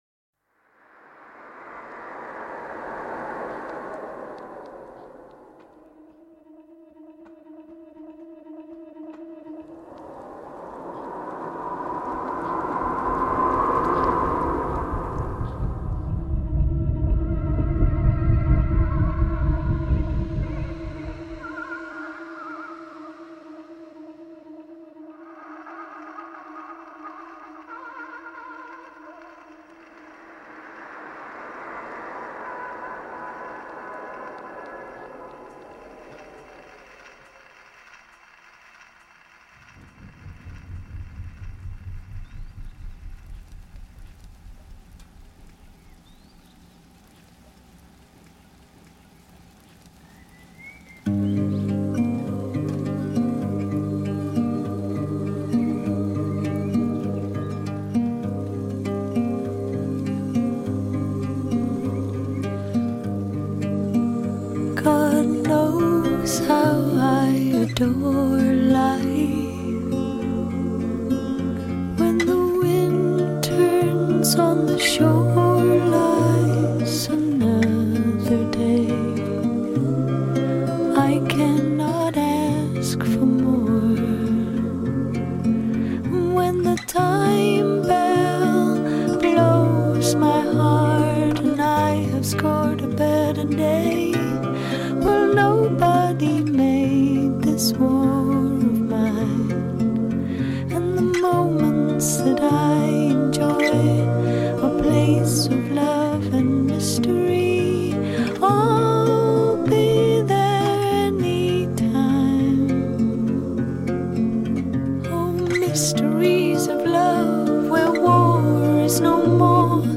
background music
jazz standard